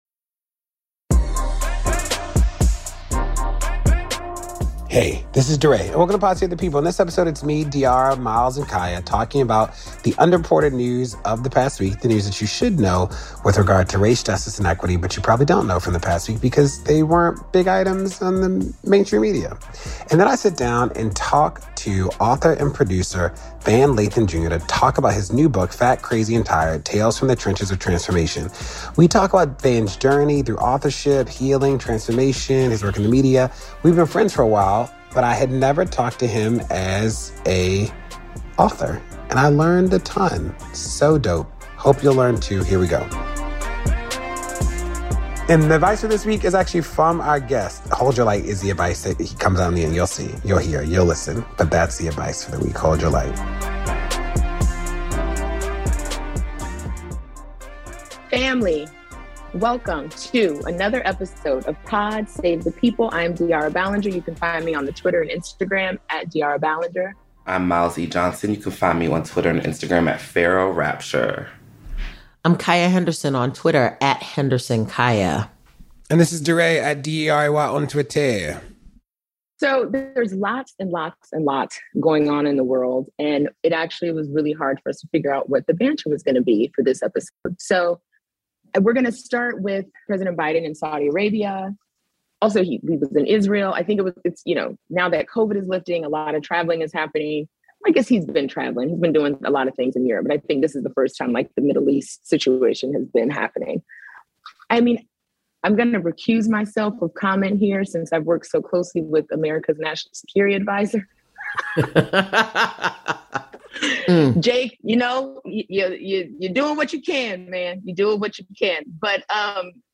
DeRay interviews author and producer Van Lathan Jr. about his new book Fat, Crazy, and Tired: Tales from the Trenches of Transformation.